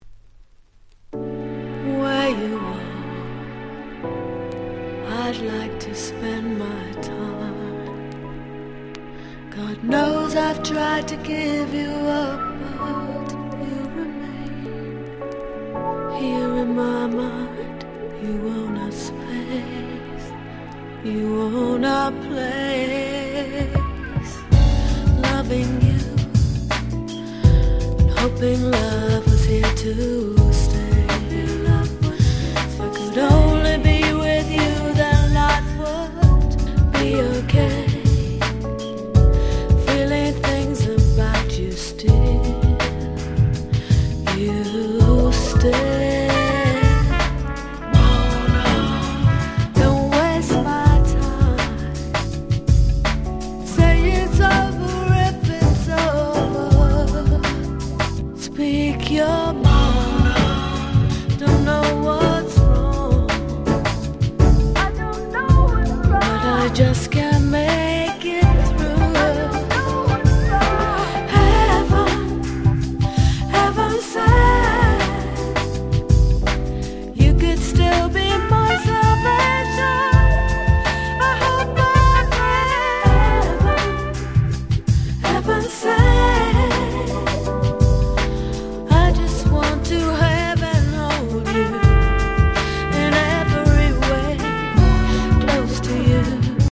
最高のUK Soul Albumです。